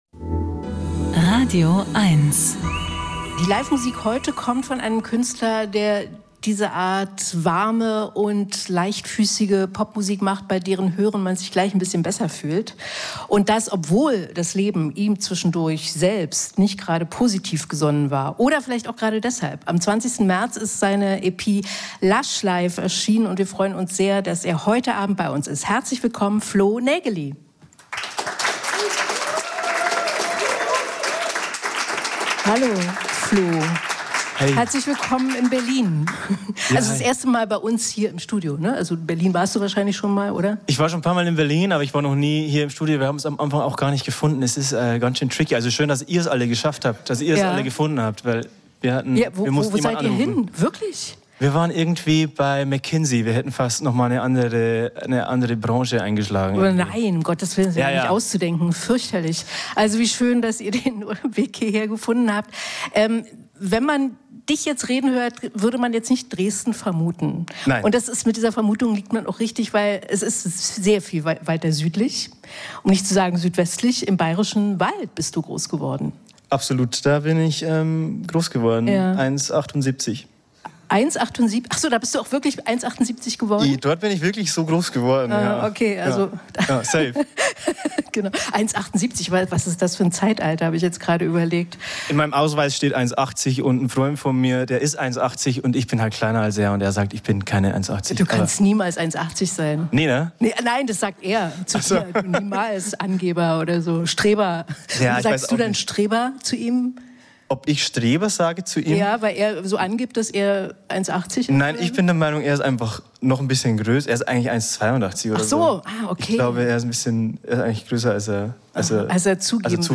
Musik-Interviews Podcast